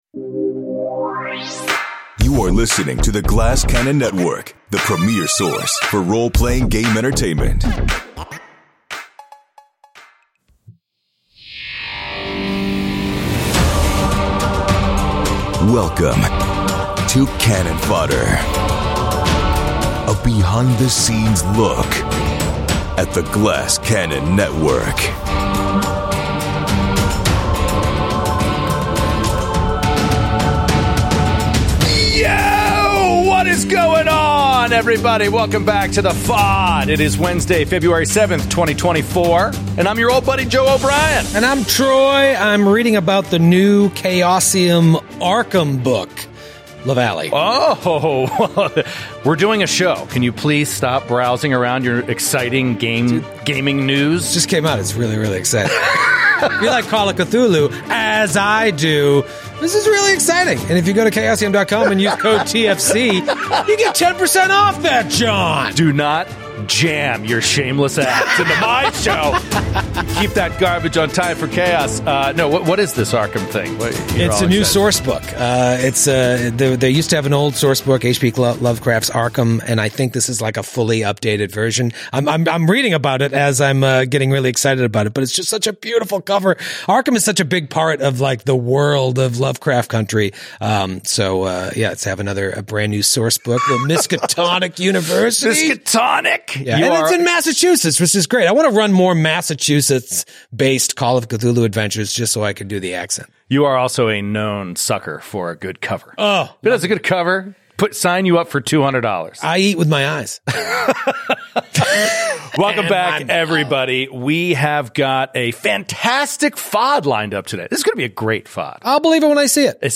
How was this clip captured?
The guys return to the studio for another dose of Fod Juice and to break some exciting news about the next GCN + Marvel collaboration. They also discuss Everyday Heroes, Abubakar Salim's new video game, and how character backstory scenes are developed and decided on for the GCP!